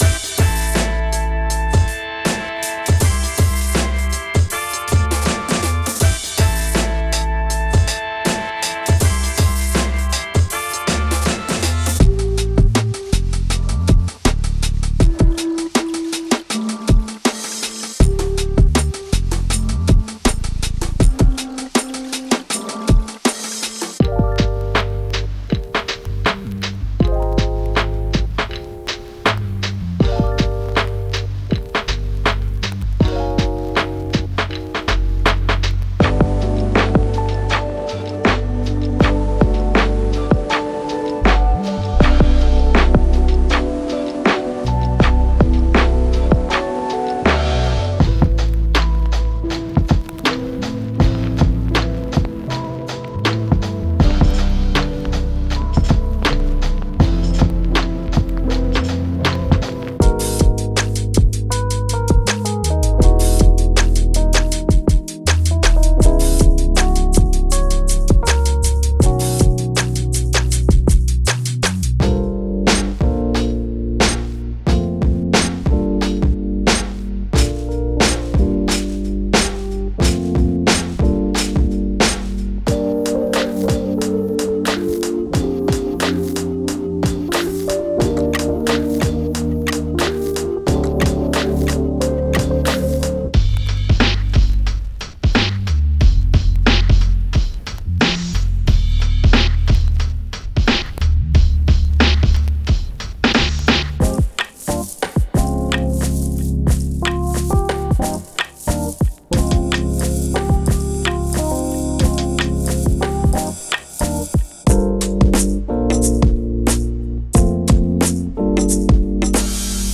Genre:Hip Hop
テンポ：80、90、95BPM
クリーンでパンチがあり、ミックス対応済みのループ
デモ内で使用されているメロディーや追加サウンドは、あくまで参考用であり、本コレクションには含まれていません。
100 Drum Loops